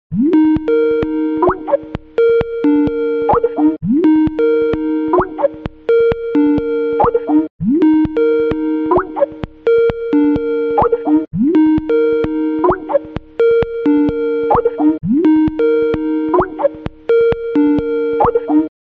Отличного качества, без посторонних шумов.